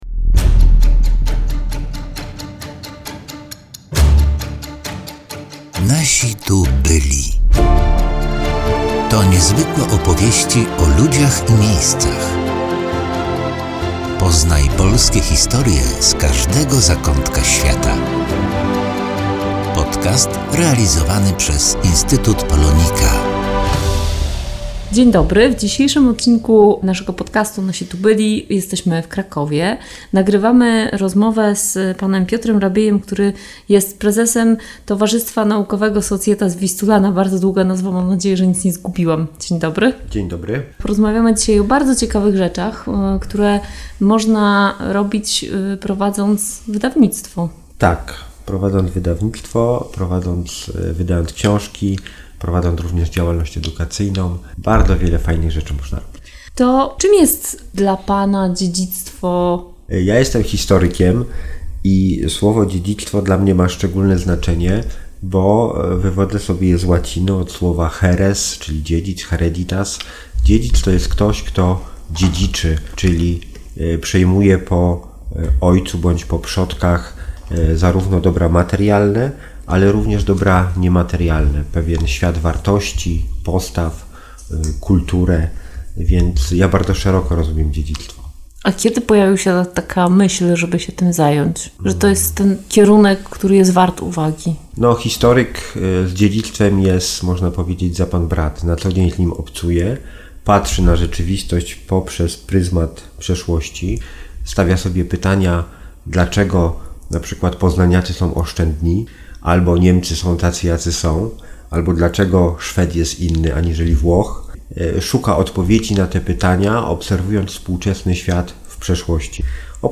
Popularyzacja wiedzy o dziedzictwie kulturowym w wydawnictwie i publikacjach naukowych Aktualność 23.09.2022 W najnowszym odcinku rozmowy w cyklu „Nasi tu byli” wracamy do Krakowa.